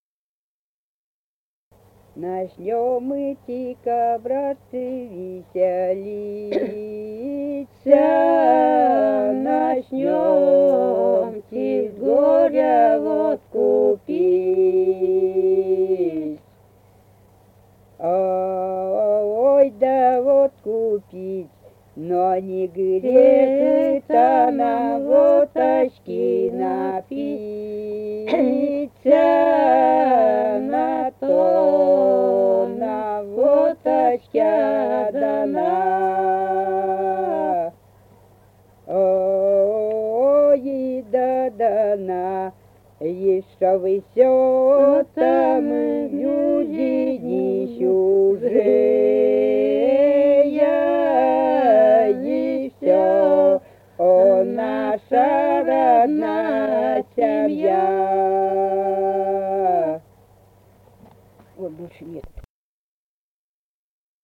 Республика Казахстан, Восточно-Казахстанская обл., Катон-Карагайский р-н, с. Белое / с. Печи.